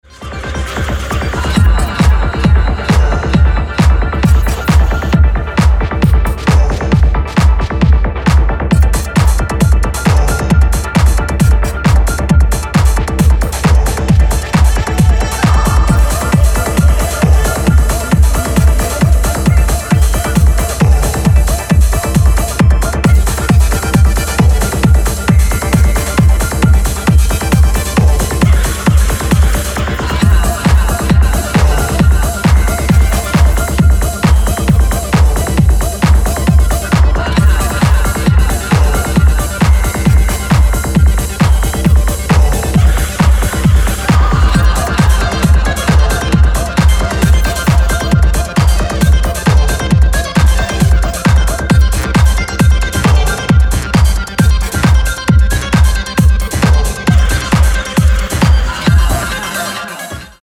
ダークで狂騒的なハウス/テクノを展開しています。